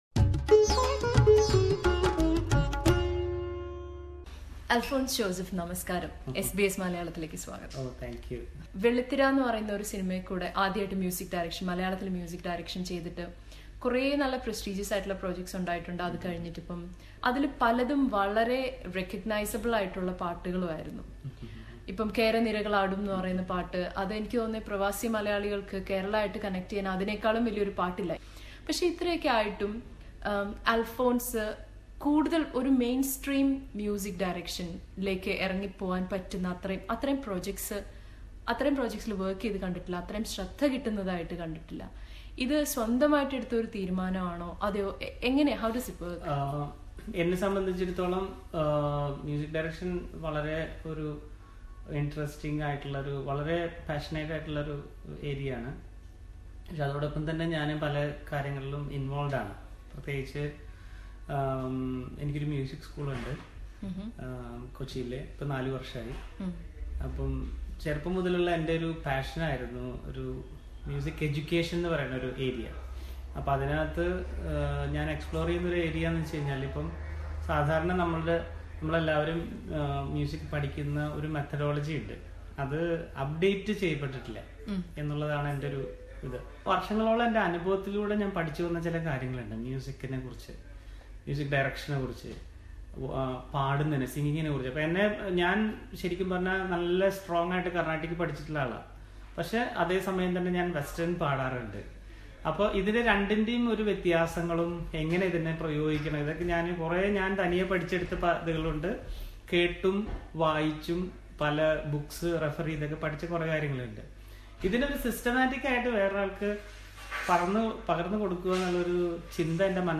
ശ്രദ്ധേയമായ നിരവധി ഗാനങ്ങള്‍ ഒരുക്കുകയും, തെന്നിന്ത്യ മുഴുവന്‍ ശ്രദ്ധിച്ച ഗാനങ്ങള്‍ ആലപിക്കുകയും ചെയ്തിട്ടുള്ള സംഗീതജ്ഞനാണ് അല്‍ഫോണ്‍സ് ജോസഫ്. റെക്‌സ് ബാന്റിന്റെ സംഗീതപരിപാടിക്കായി ഓസ്‌ട്രേലിയയിലെത്തിയ അല്‍ഫോണ്‍സ്, എസ് ബി എസ് മലയാളവുമായി സംസാരിച്ചു. ആ അഭിമുഖം കേള്‍ക്കാം, മുകളിലെ പ്ലേയറില്‍ നിന്ന്.